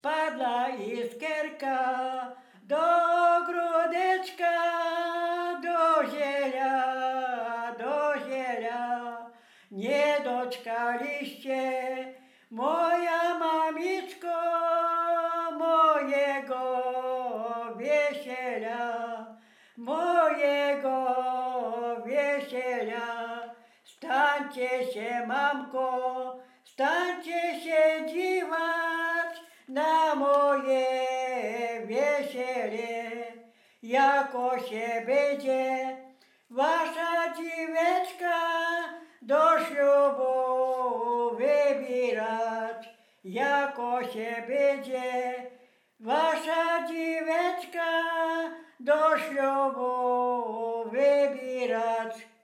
województwo dolnośląskie, powiat lwówecki, gmina Lwówek Śląski, wieś Zbylutów
Weselna sieroca
weselne wesele błogosławieństwo